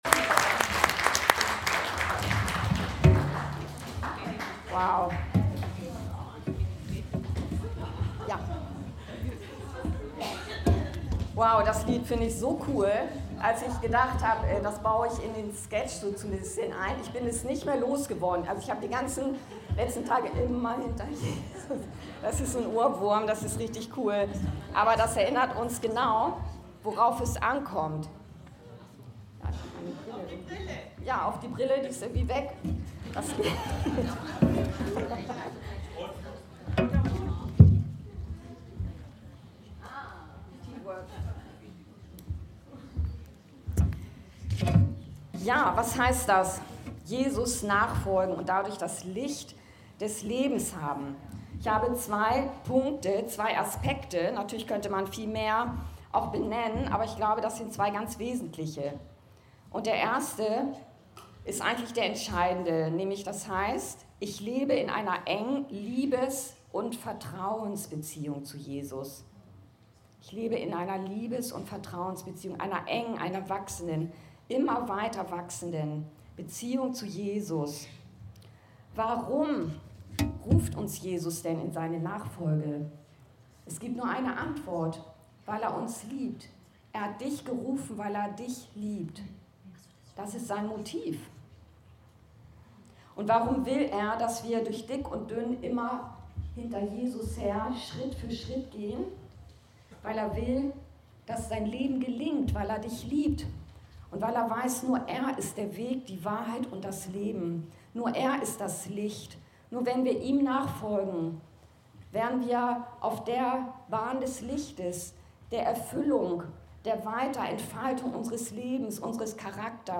Predigten und Lehre aus der Anskar-Kirche Hamburg-Mitte